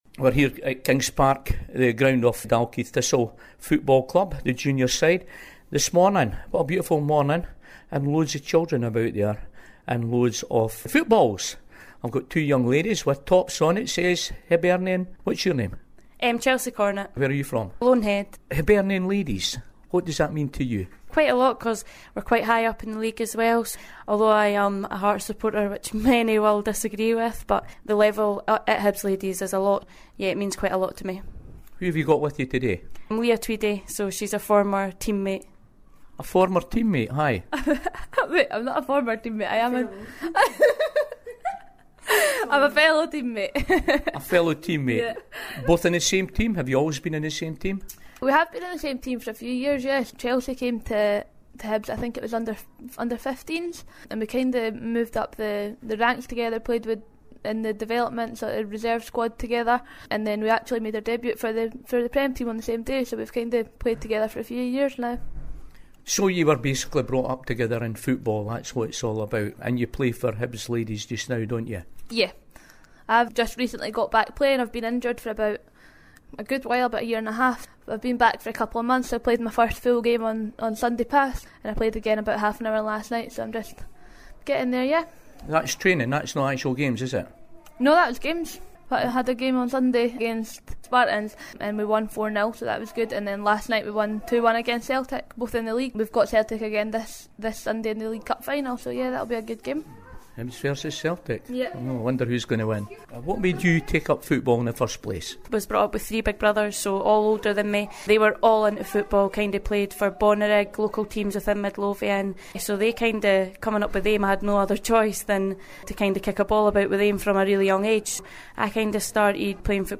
Here we have two young ladies from the Hibernian ladies football team talking about the Tesco Bank football challenge and their team